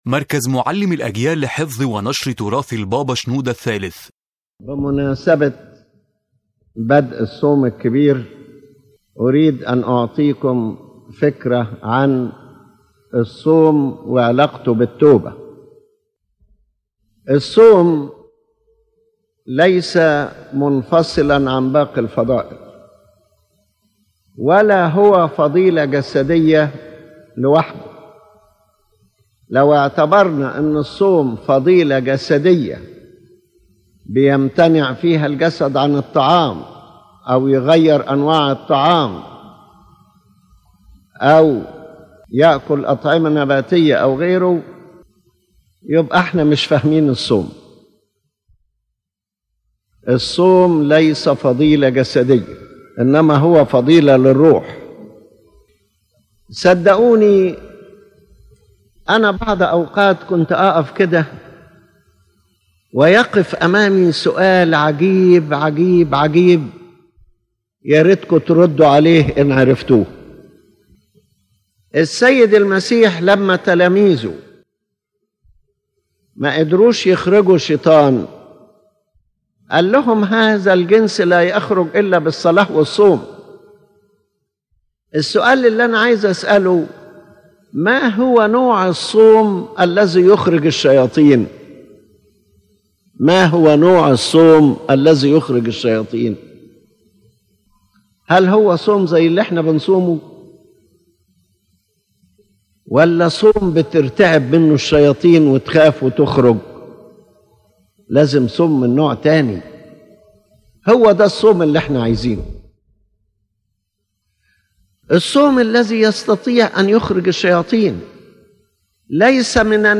The General Message of the Lecture
⬇ تحميل المحاضرة The General Message of the Lecture His Holiness Pope Shenouda III speaks about the Great Lent as a spiritual path of repentance and inner transformation, not merely bodily abstinence from food. True fasting is the fasting of the heart and the soul together, connected with prayer, repentance, and the love of God.